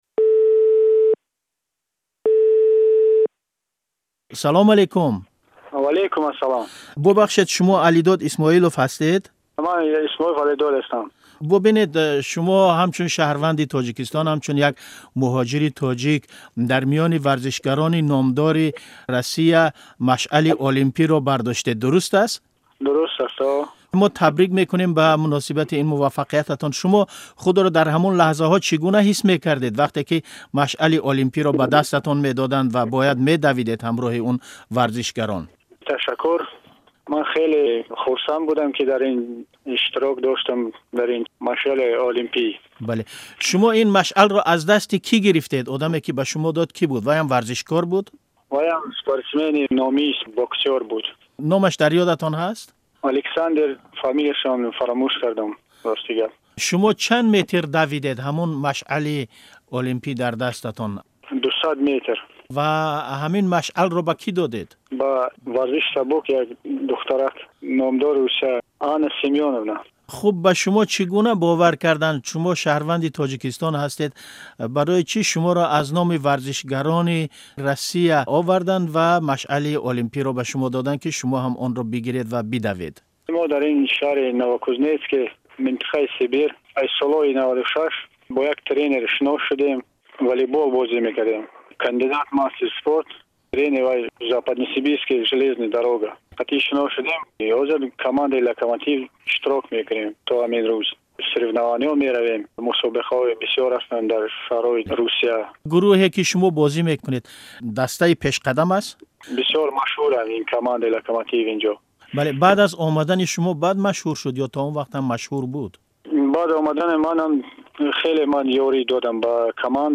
Гуфтугӯи Озодӣ